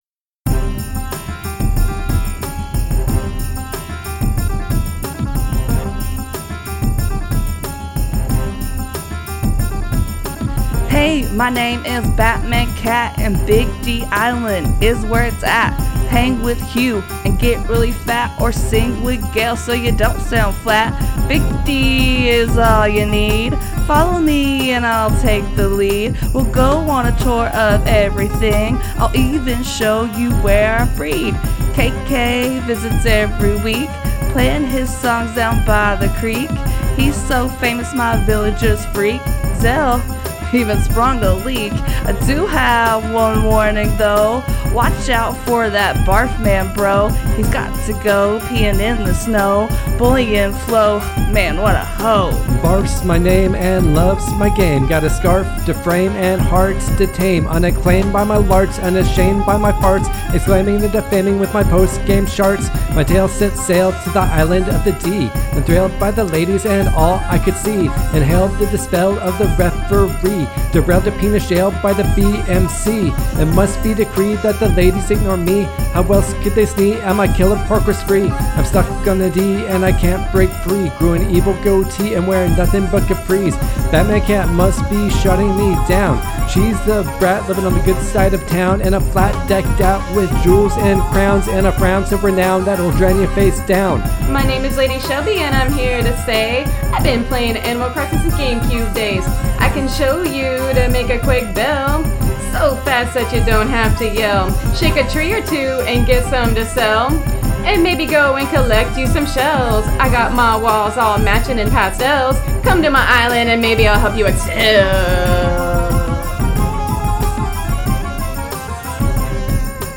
Rap from Episode 28: Animal Crossing: New Horizons – Press any Button
Animal-Crossing-Rap.mp3